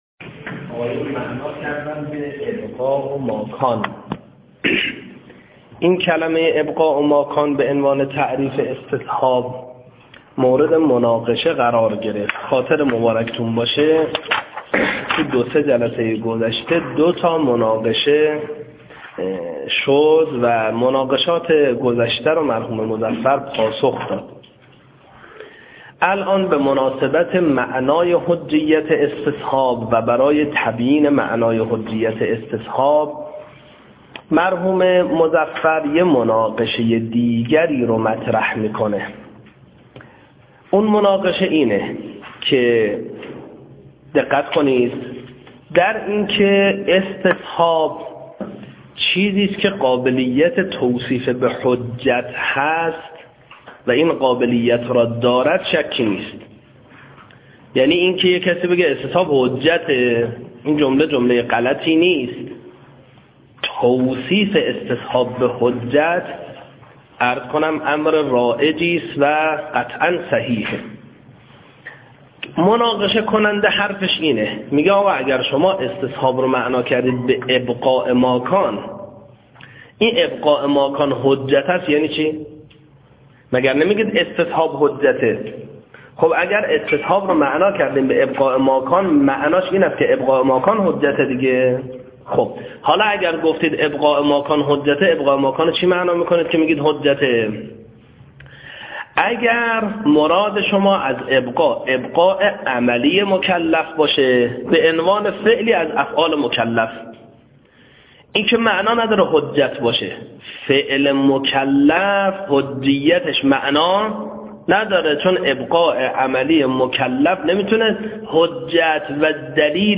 صوت دروس حوزوی- صُدا